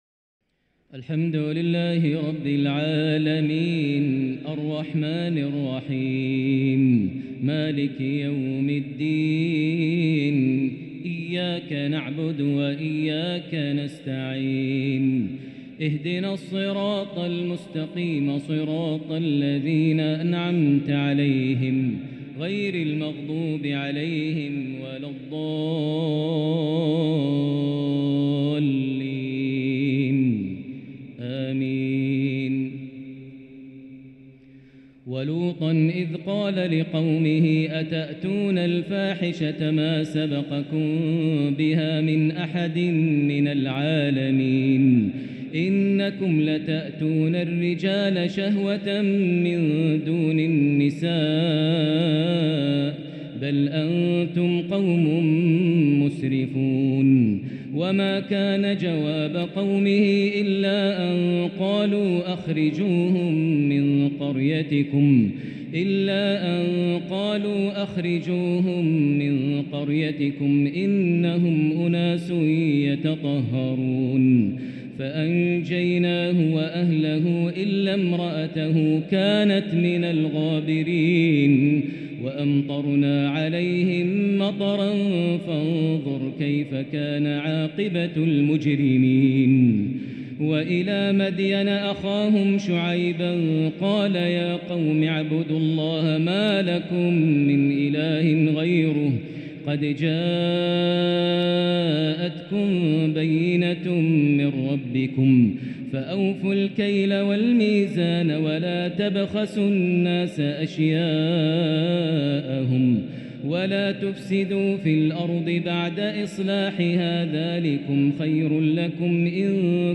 تراويح ليلة 11 رمضان 1444هـ من سورة الأعراف (80-141) | taraweeh 11st niqht ramadan Surah Al-A’raf 1444H > تراويح الحرم المكي عام 1444 🕋 > التراويح - تلاوات الحرمين